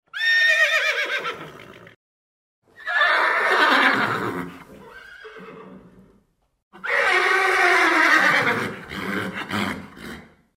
Звуки ржания лошади
Ржание жеребцов